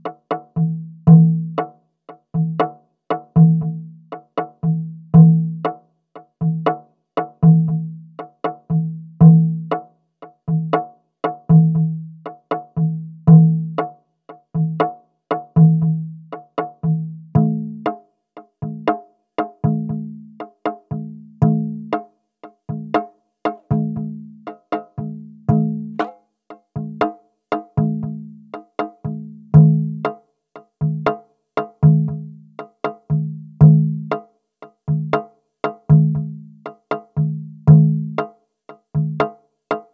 Drum Modelling Examples